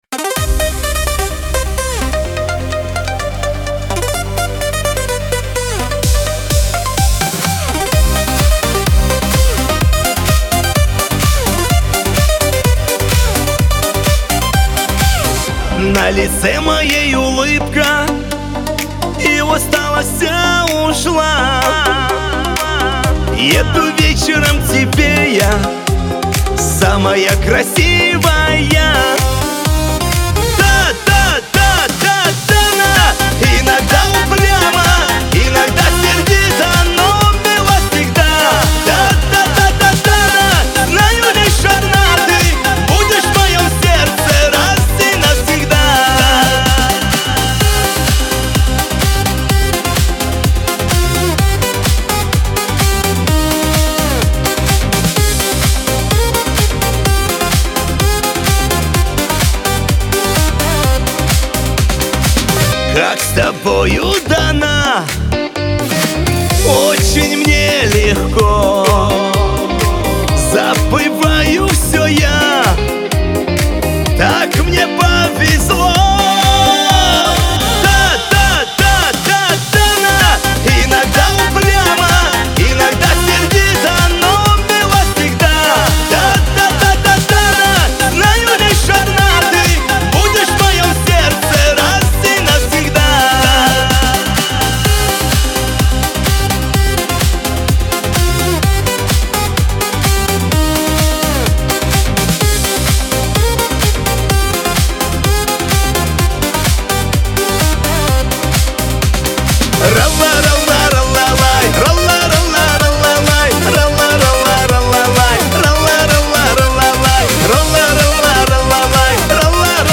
Лирика
Кавказ поп